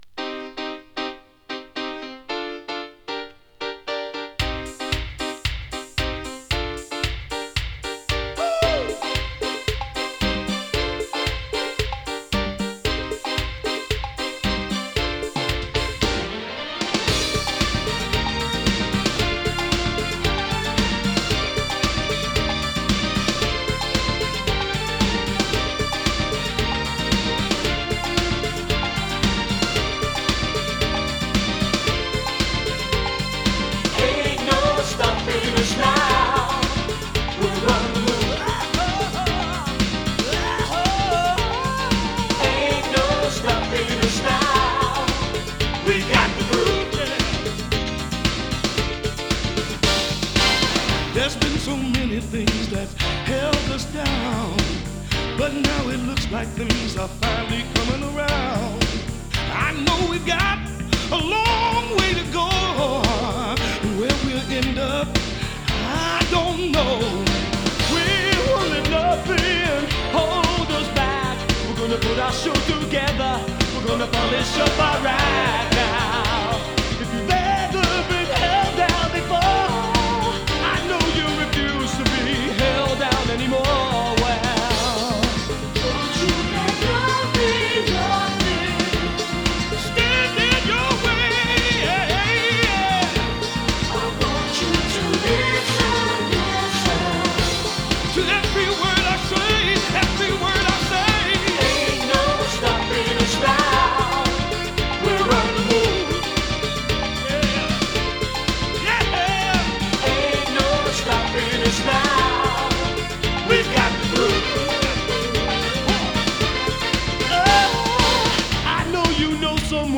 フィリーソウル
モダンソウル